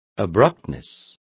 Also find out how brusquedad is pronounced correctly.